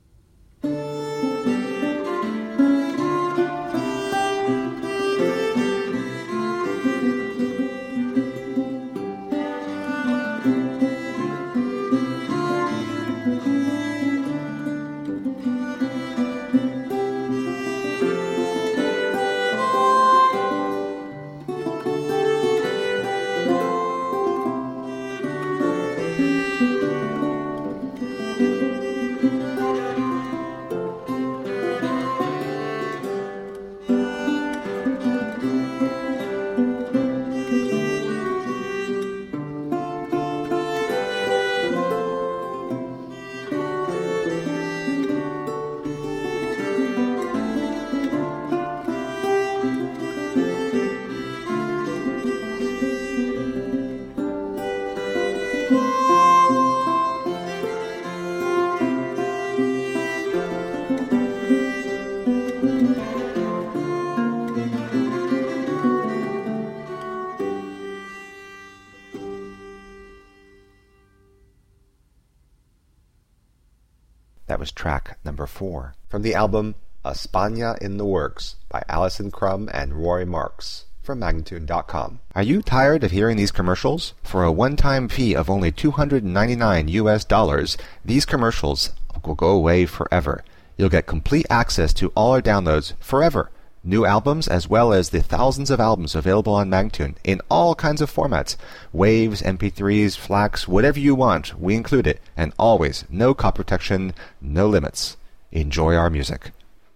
Classical, Renaissance, Instrumental
Lute, Viola da Gamba